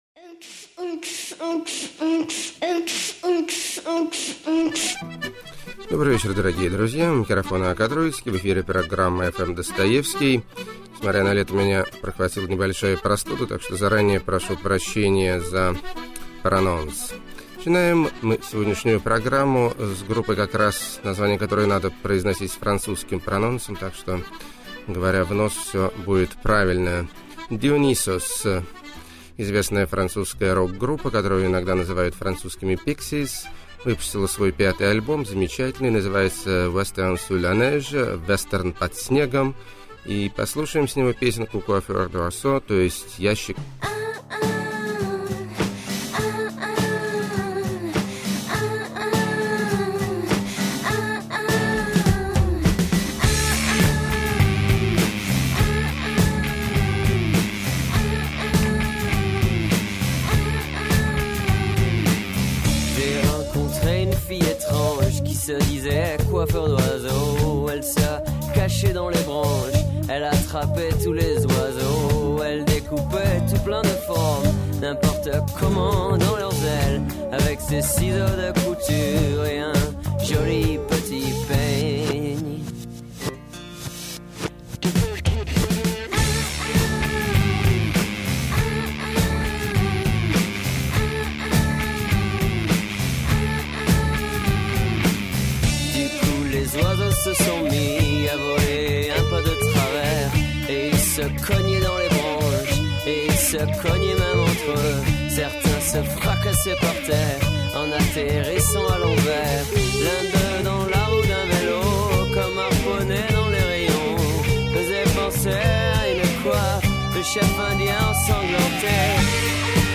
Народные Гитарные Виртуозы Рвут Струны И Души.
Духоподъёмный Евро-китч.
Американская Депресуха С Английской Элегантностью.
Шикарные Дамские Баллады.
Эпилептический Рок/блюз.